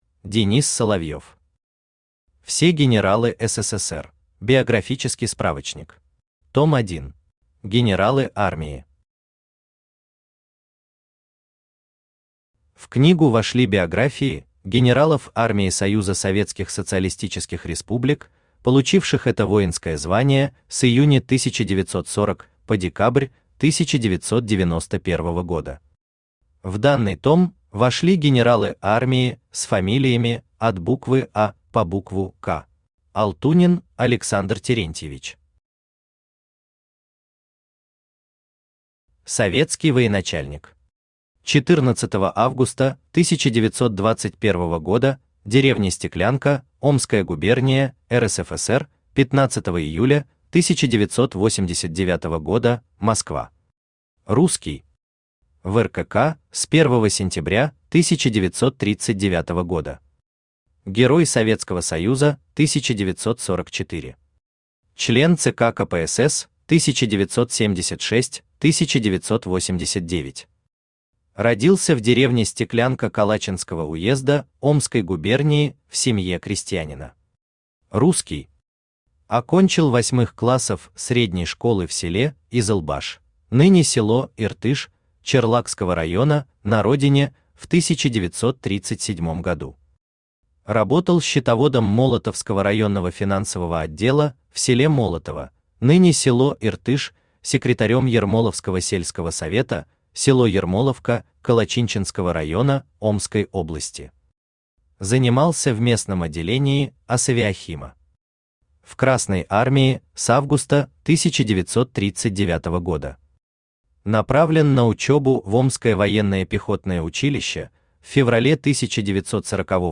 Том 1 Автор Денис Соловьев Читает аудиокнигу Авточтец ЛитРес.